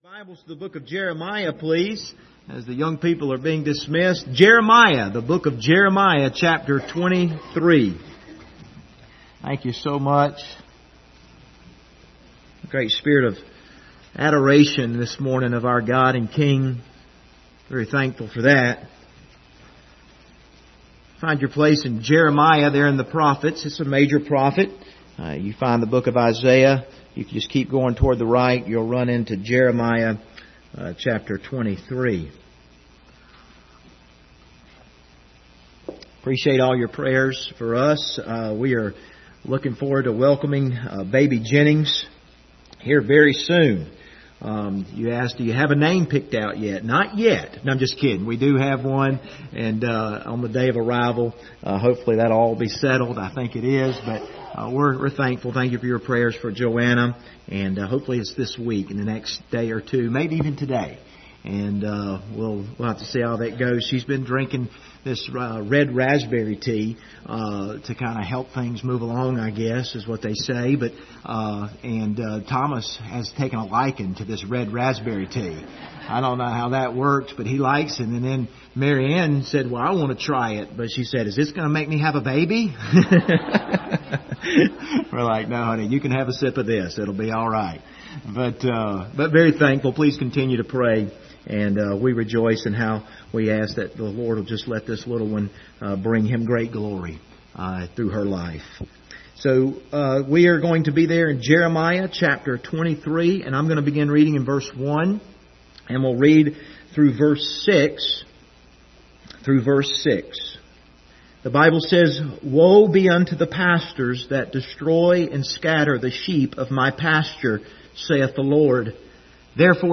Passage: Jeremiah 23:1-6 Service Type: Sunday Morning